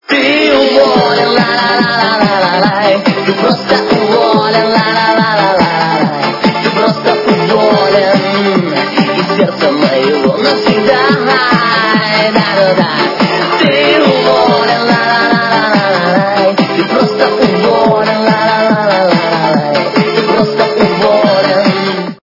украинская эстрада
качество понижено и присутствуют гудки